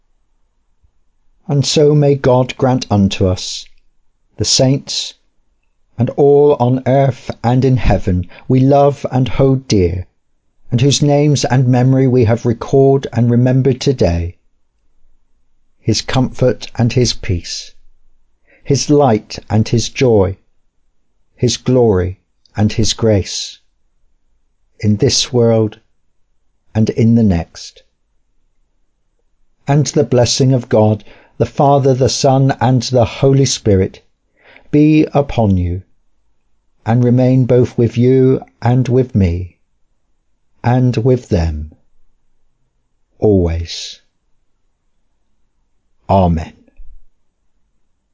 Welcome & Call to Worship